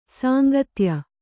♪ sāngatya